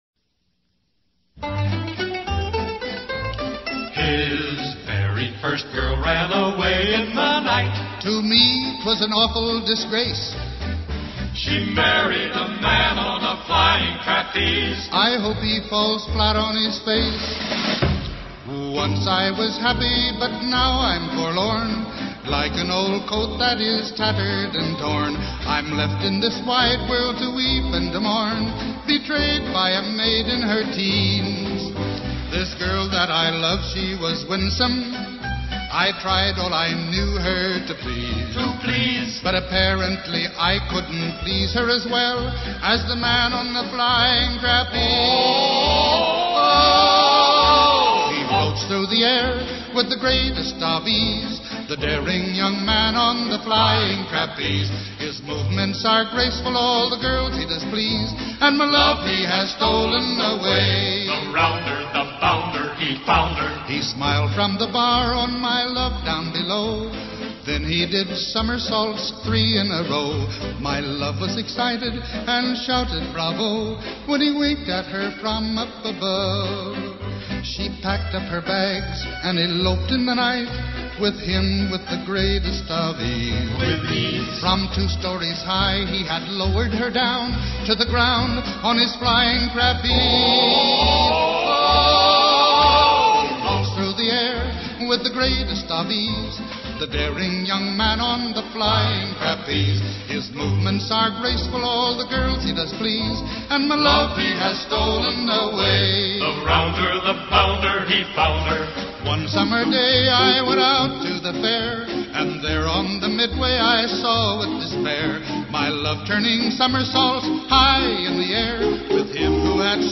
It’s called “The Man on the Flying Trapeze.” It’s a fun ballad written in the 1870s about a young gentleman who takes his lady friend to the circus. She sees the man on the flying trapeze and falls head over heels in love.
The song is sung perfectly by Burl Ives.